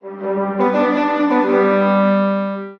fbrawl_match_start.ogg